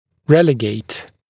[‘relɪgeɪt][‘рэлигейт]низводить, переводить в низший разряд, класс